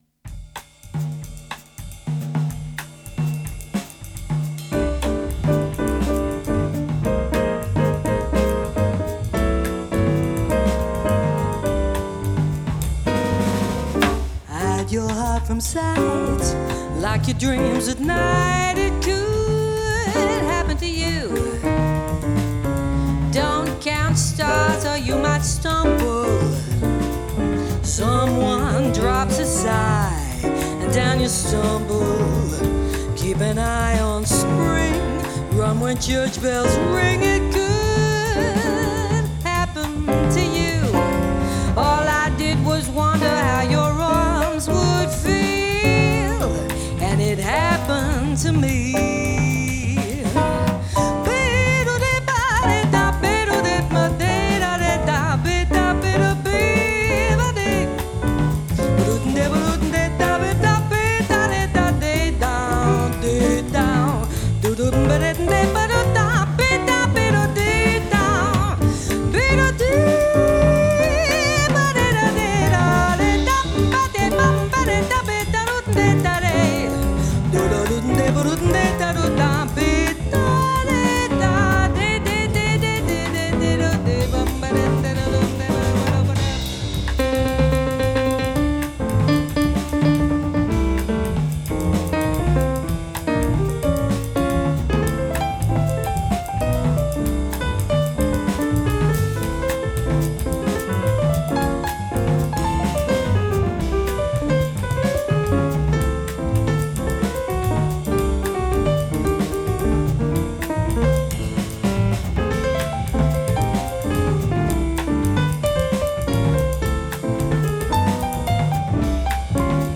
VOCALS
PIANO
BASS
DRUMS